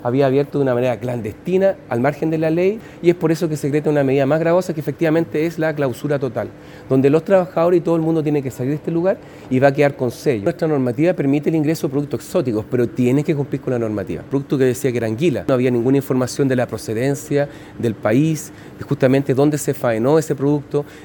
En el lugar, el seremi de Salud regional, Gonzalo Soto, señaló que el recinto había violado una disposición sanitaria.